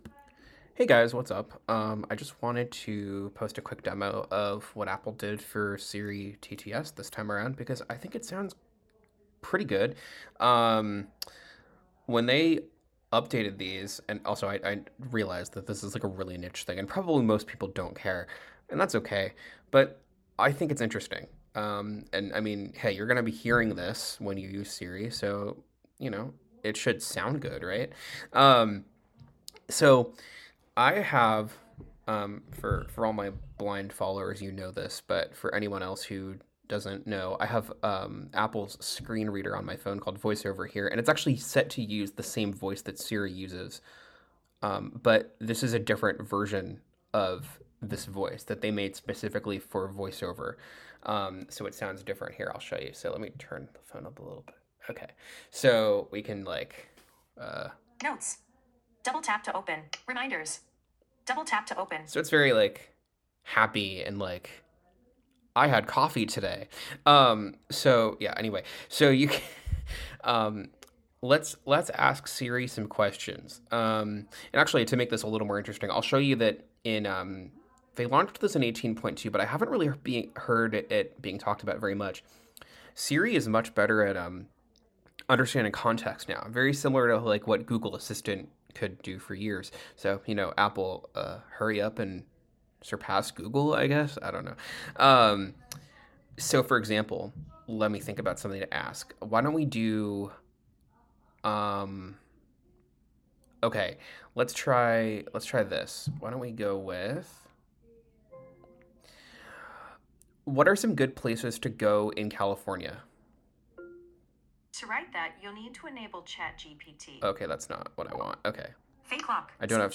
Attached: 1 audio If you want to listen to some guy demo Siri and talk about TTS for four minutes and eleven seconds, here you go lmao. I talk about Siri's new TTS improvements in iOS 18.4, and try unsuccessfully to get info about places in California. Amazingly, I have much better luck with getting answers about machine learning and what it's used for.